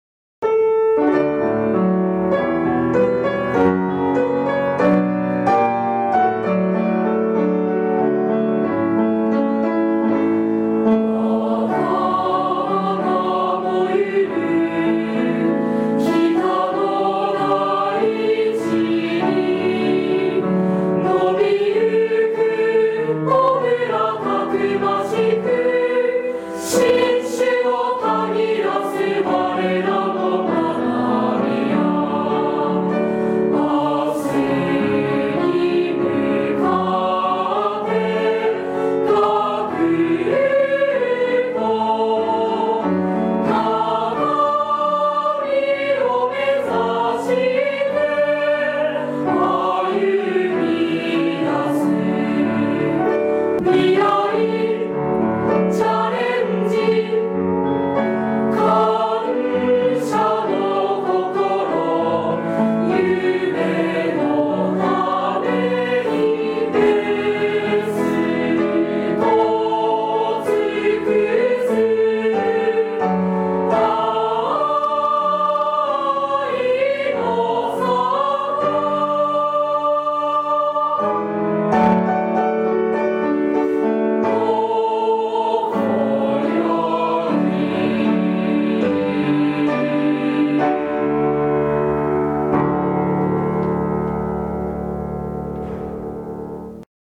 北海道札幌あいの里高等支援学校校歌2026.mp3